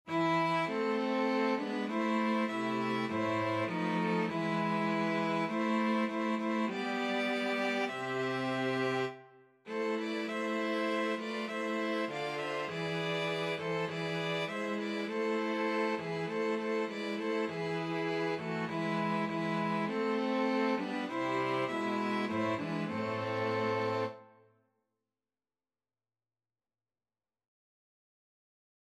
String Quartet version
Christmas
4/4 (View more 4/4 Music)
String Quartet  (View more Easy String Quartet Music)
Classical (View more Classical String Quartet Music)